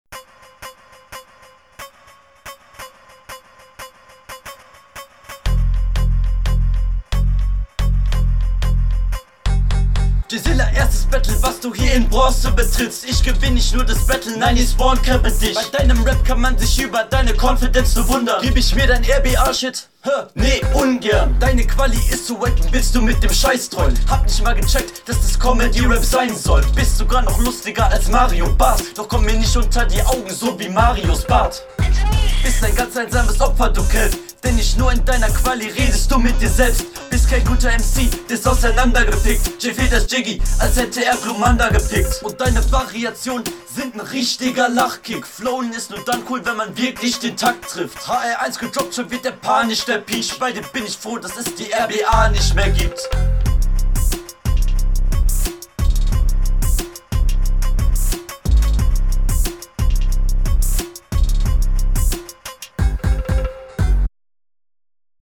spawncampenline nice marios bart line leider nich ganz cooler beat du hast nichtmehr so viele …